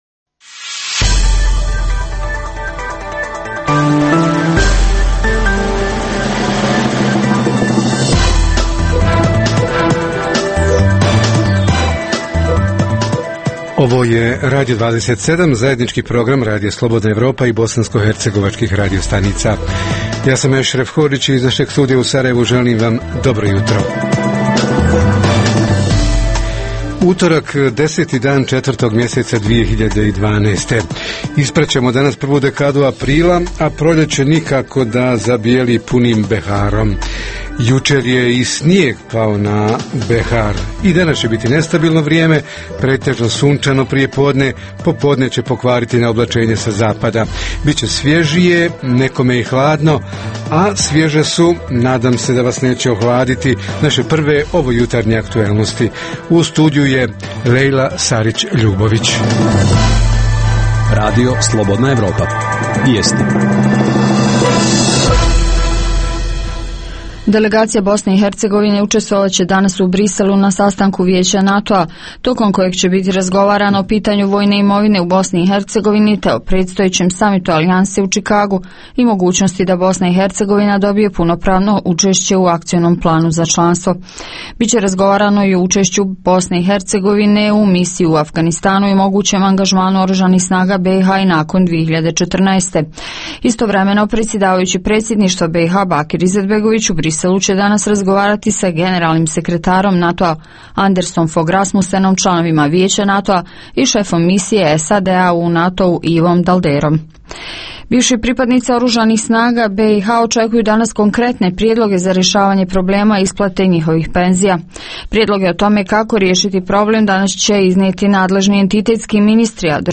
Tema jutra: Zdravstvene usluge i liste čekanja – gdje i zašto su najduže i šta se poduzima kako bi pacijent blagovremeno dobio propisanu uslugu? Reporteri iz cijele BiH javljaju o najaktuelnijim događajima u njihovim sredinama.
Redovni sadržaji jutarnjeg programa za BiH su i vijesti i muzika.